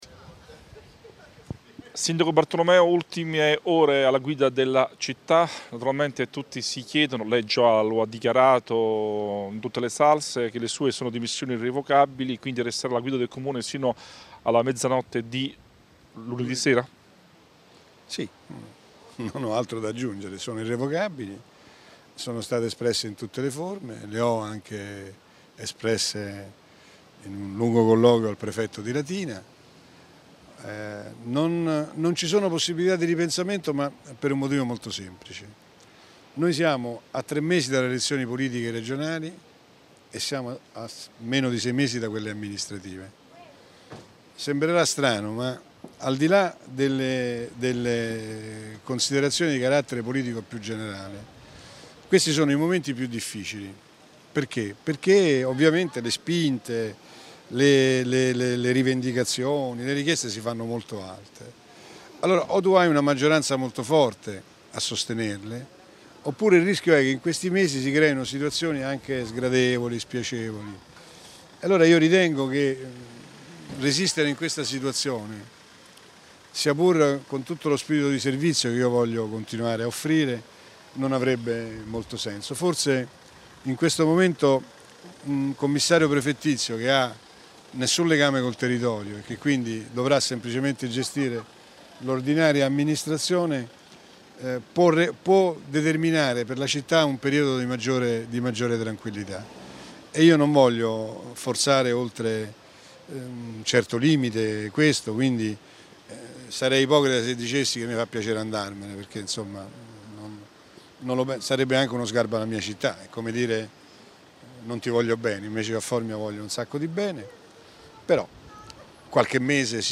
Intervista al sindaco Sandro Bartolomeo (audio)